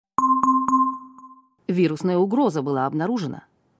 В коллекции – системные оповещения, сигналы сканирования и другие характерные аудиофрагменты длиной от 1 до 6 секунд.
Вирусная угроза выявлена